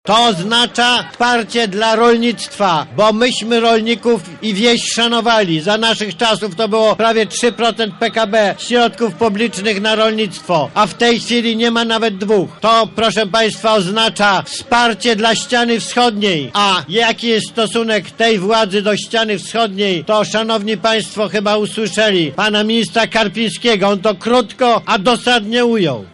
W ostatni dzień kampanii prezes spotkał się z wyborcami PiSu przekazując im proste hasło: Zwyciężymy.
– mówi prezes Jarosław Kaczyński